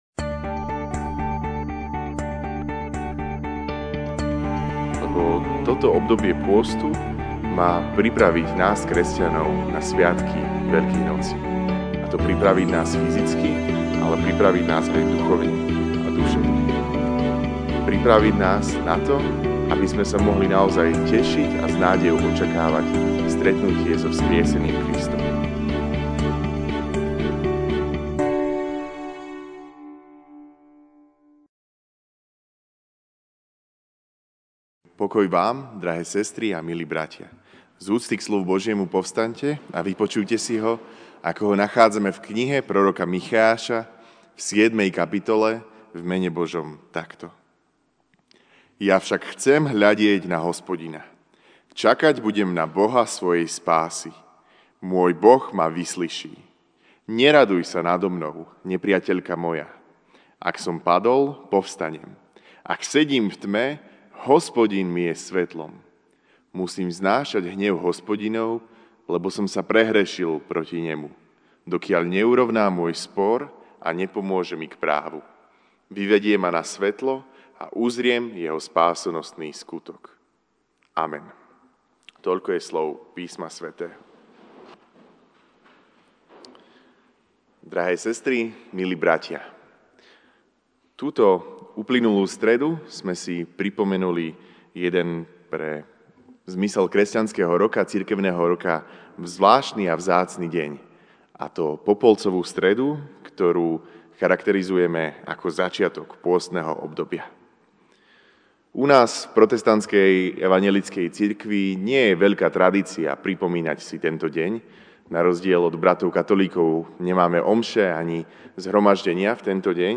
mar 10, 2019 Vykonávanie pôstu MP3 SUBSCRIBE on iTunes(Podcast) Notes Sermons in this Series Večerná kázeň: Vykonávanie pôstu (Mich 7, 7-9) Ja však chcem hľadieť na Hospodina, čakať budem na Boha svojej spásy.